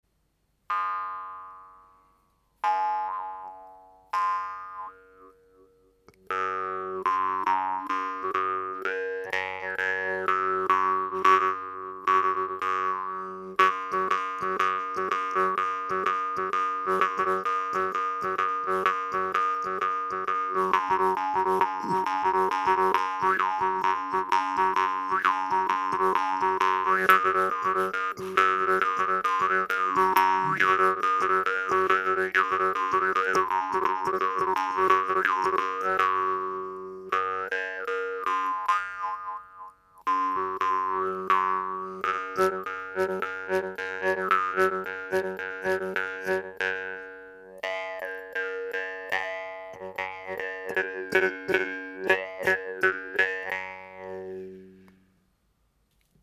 MARRANZANO PICCOLO
La prise en main et agréable, la languette juste assez souple pour permettre une bonne attaque, dans des jeux rapides ou pas.